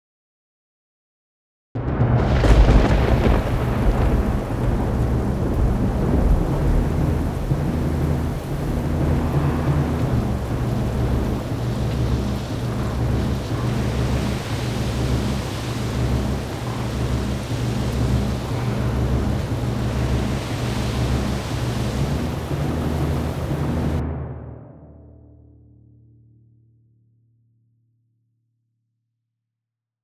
The opening storm. Drums, a ship at sea, and a little bit of magic.